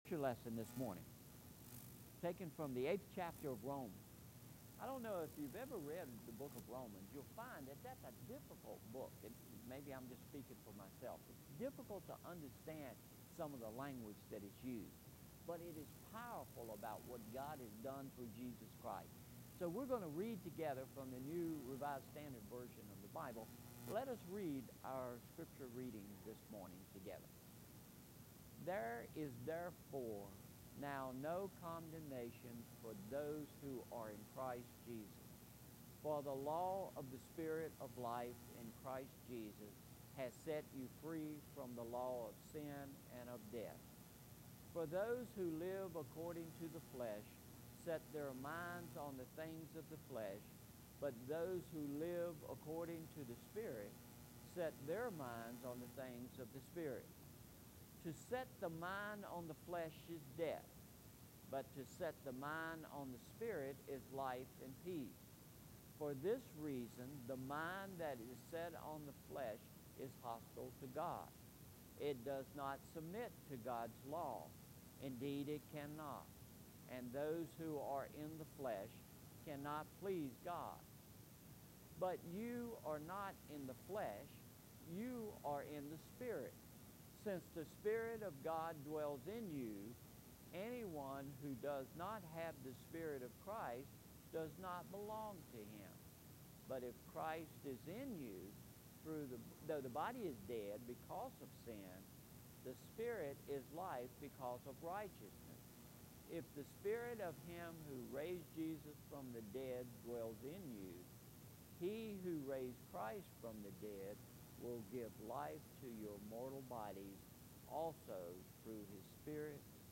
Message Aim: (Fifth Sunday of Lent) God created us to live through the death of Jesus and the power of the Holy Spirit at work in us.